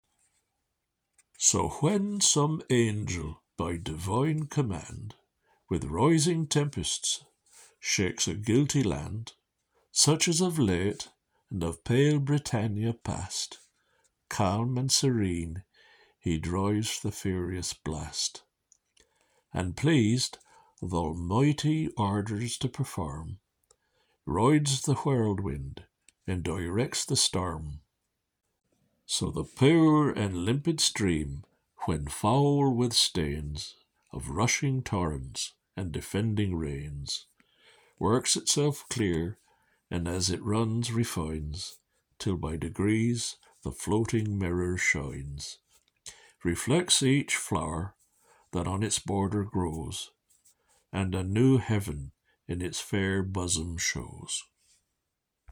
Readings from Franklin’s Poor Richard’s Almanack and his Reformed Mode of Spelling